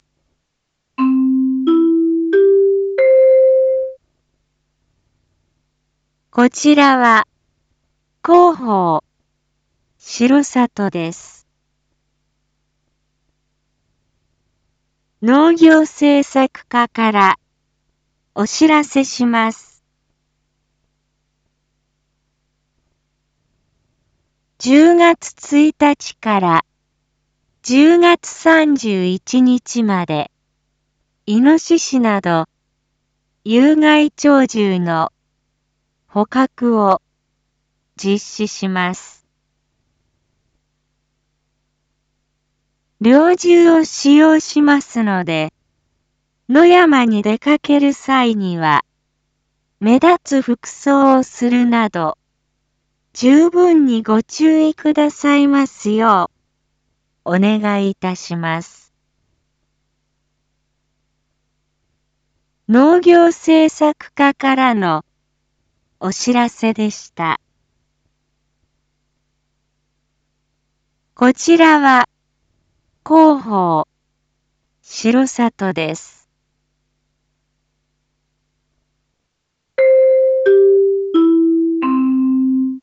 Back Home 一般放送情報 音声放送 再生 一般放送情報 登録日時：2023-10-06 19:01:24 タイトル：（金）有害鳥獣捕獲について インフォメーション：こちらは、広報しろさとです。